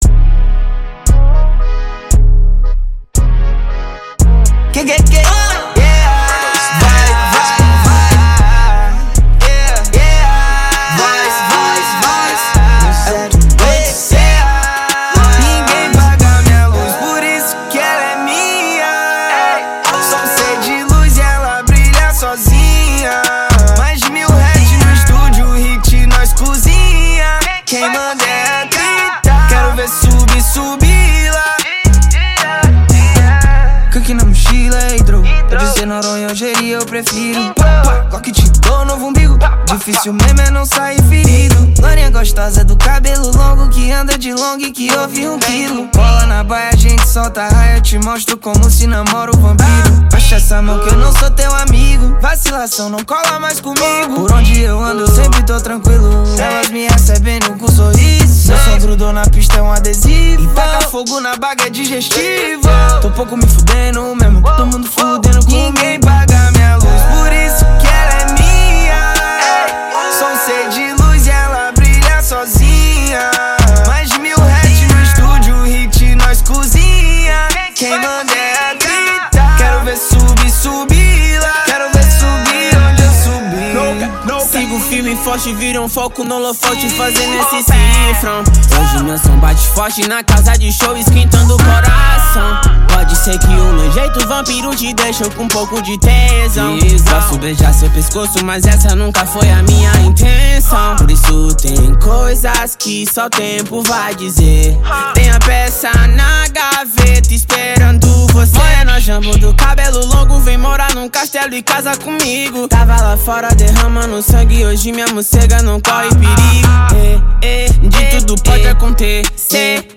2024-04-08 18:53:23 Gênero: Trap Views